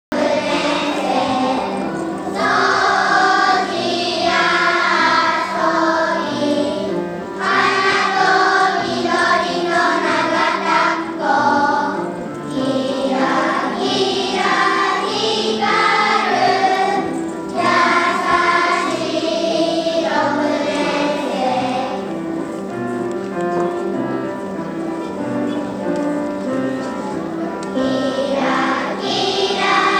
6年生を送る会　音声付き
体育館に集まって対面での「6年生を送る会」が5年ぶりに行われました。
1年生の発表　きらきら星の替え歌